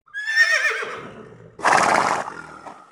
Другие рингтоны по запросу: | Теги: лошадь, ржание